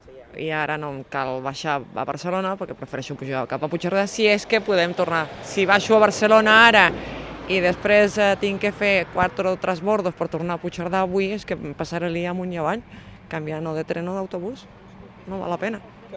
Una passatgera afectada per l’incendi a l’R3: “Hem sentit olor de cremat i hem baixat pitant del tren” ( Àudio 1 )